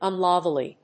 発音記号
• / `ʌnlˈʌvli(米国英語)